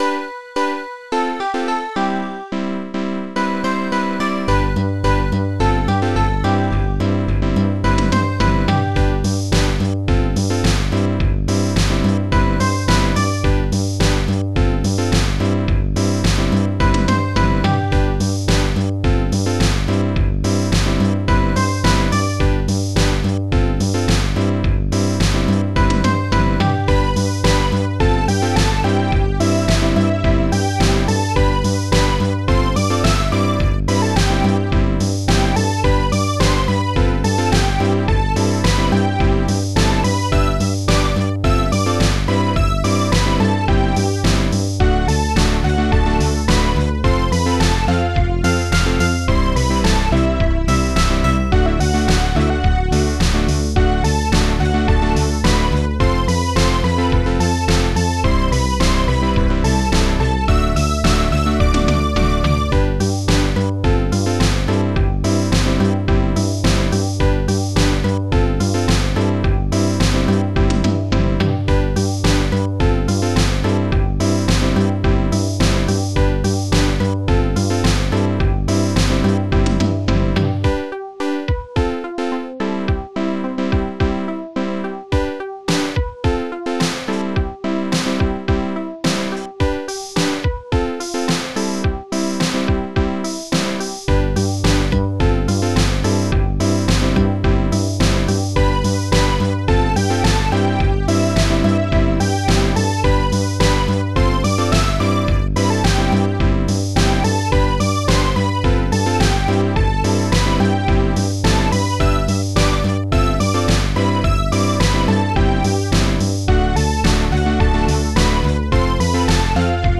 ST-01:Strings1
ST-04:synthtom
ST-02:bassdrum
ST-02:coolcymbal
ST-01:Shaker